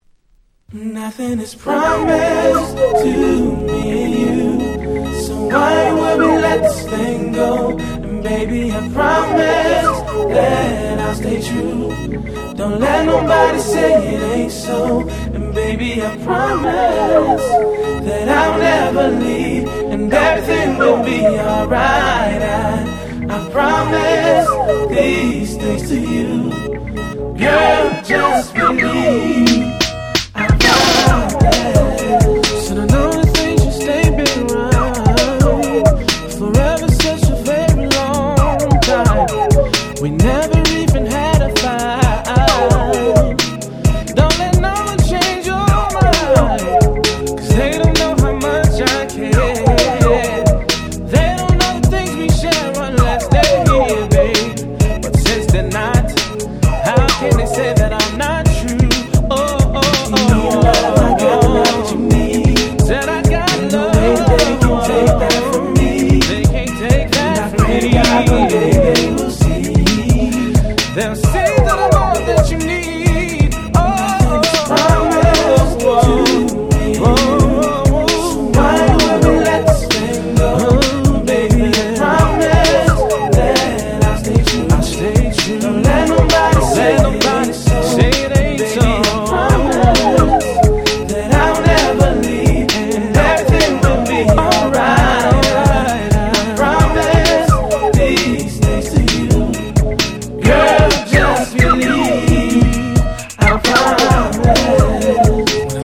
99' Super Hit R&B / Slow Jam !!